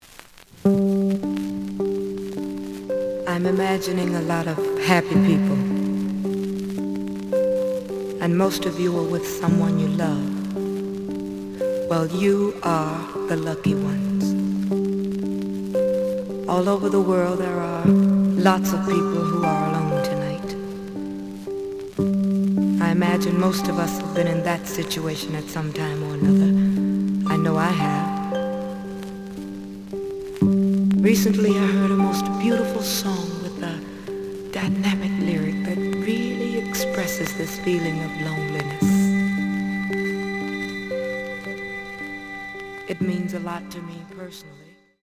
The audio sample is recorded from the actual item.
Slight noise on both sides.)